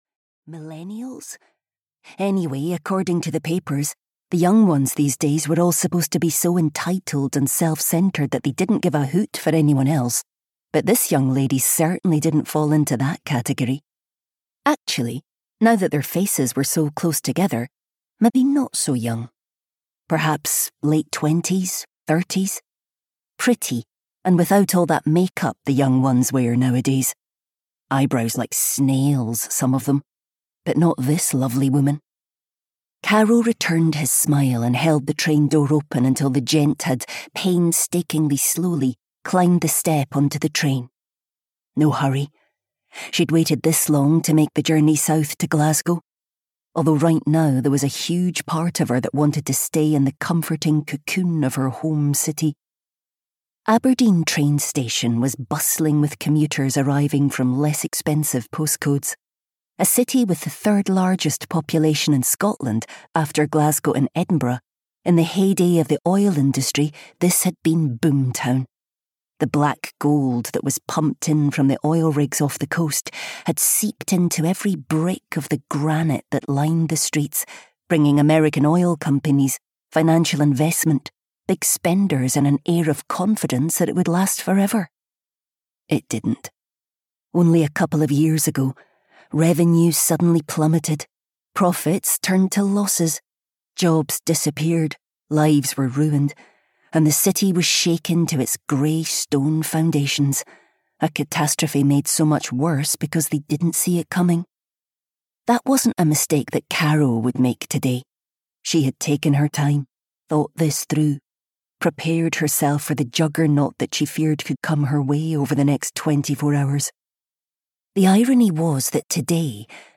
One Day in Winter (EN) audiokniha
Ukázka z knihy